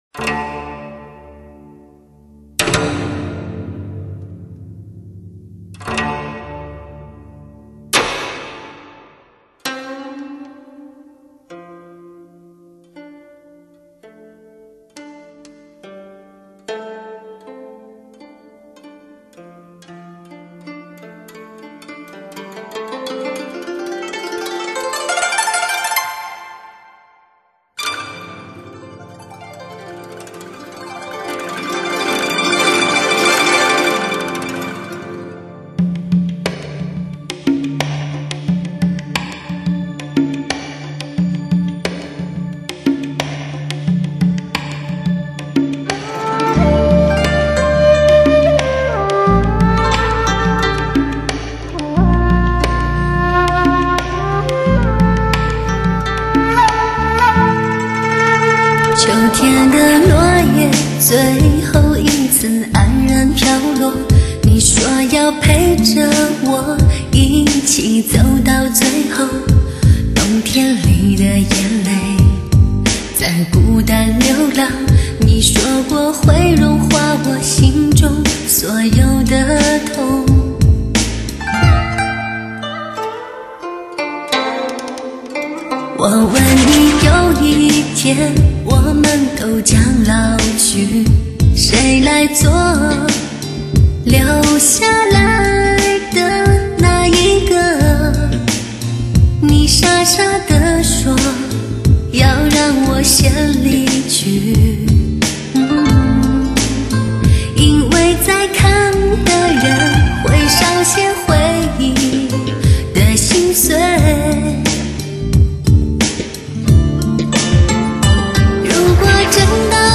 当红伤感歌曲精心收录